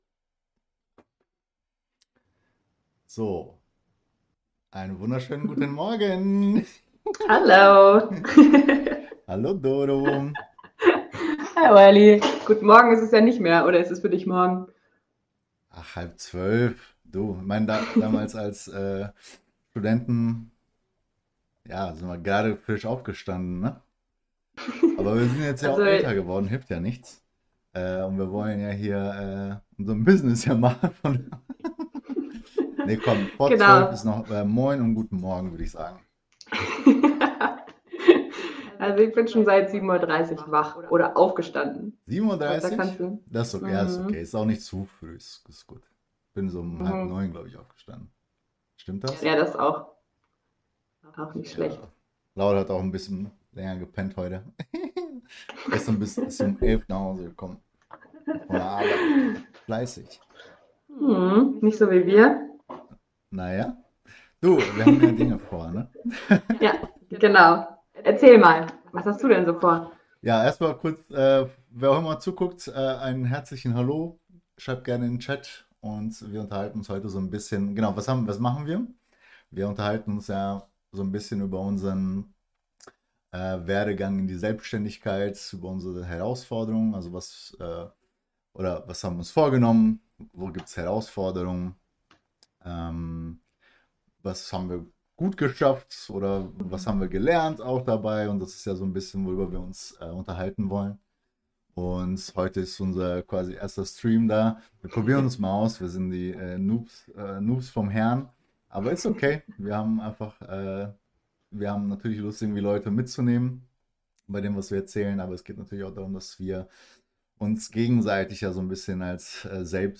Trotz technischer Schwierigkeiten diskutieren sie zukünftige Ziele und Visionen.